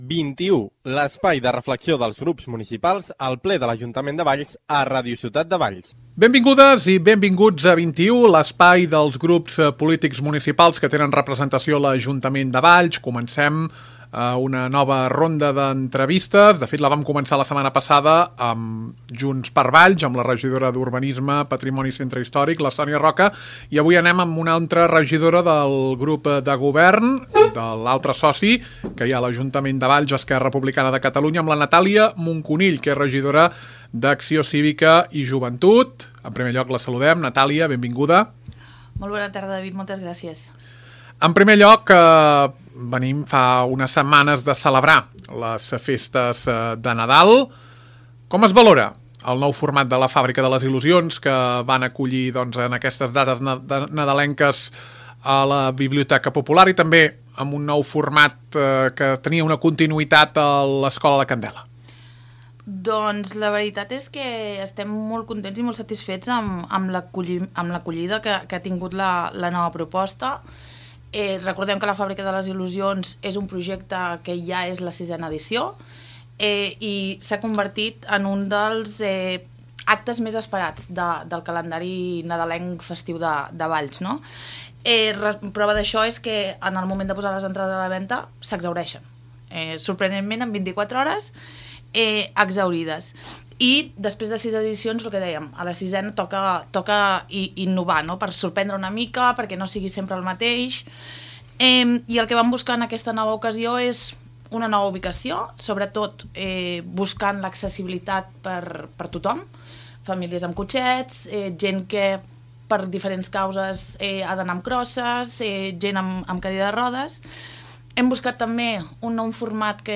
Nova temporada de 21, l’espai de reflexió dels grups municipals que tenen representació al ple de l’Ajuntament de Valls. Avui és el torn d’Esquerra Republicana de Catalunya, grup municipal que pertany a l’equip de govern amb 5 regidors. Parlem amb la regidora d’Acció Cívica i Joventut; Transparència i TIC, Natàlia Moncunill.